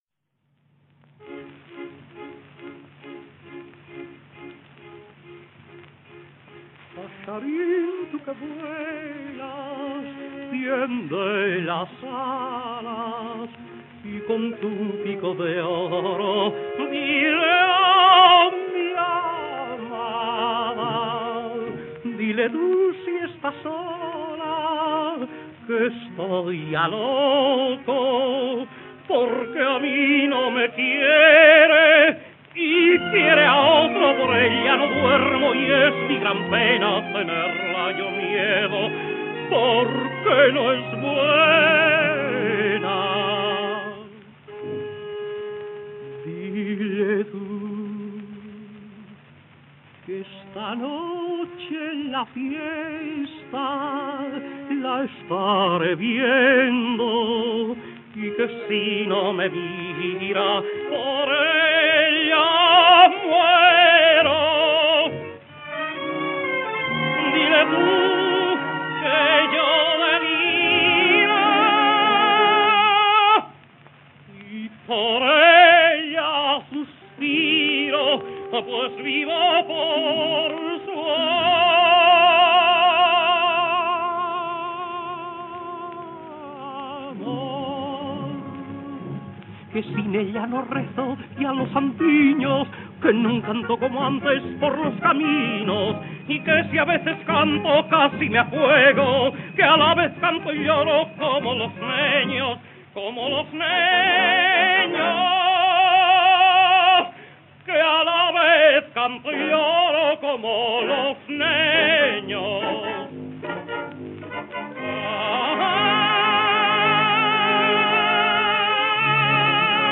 Tino Folgar sings La pícara molinera (Luna):